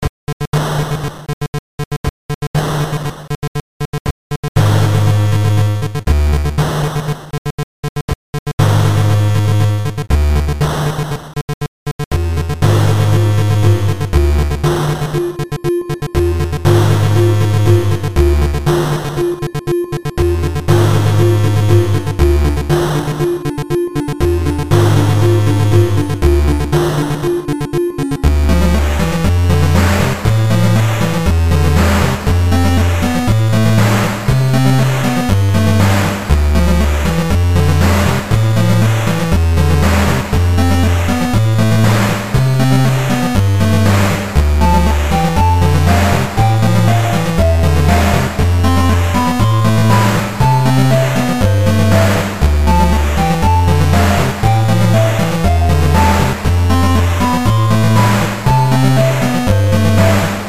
So if you like music that sounds like it was made for a NES and don’t mind abrupt endings, you can check out some of the stuff I’ve been working on: